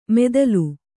♪ medalu